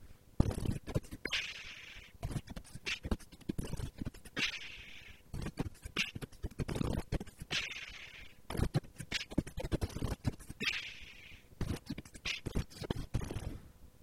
Медленный бит